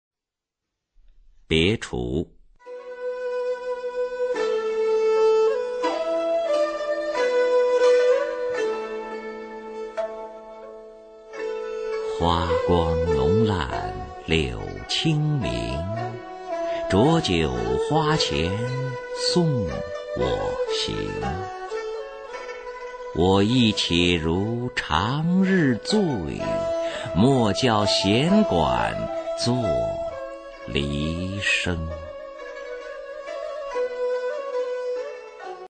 [宋代诗词朗诵]欧阳修-别滁 古诗词诵读